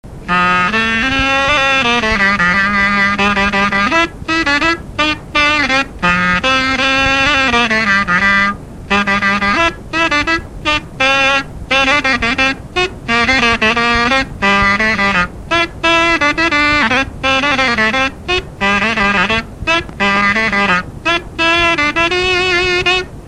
Chants brefs - A danser
danse : scottish
Pièce musicale inédite